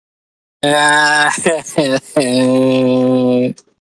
Reactions
Girl Crying